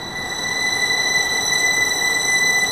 Index of /90_sSampleCDs/Roland - String Master Series/STR_Vlns Bow FX/STR_Vls Sordino